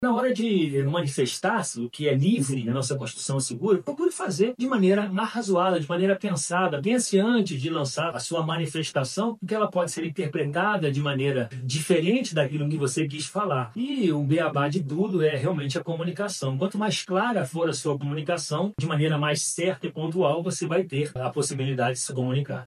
SONORA-2-ALERTA-POLICIA-.mp3